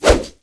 hellhound_siwsh.wav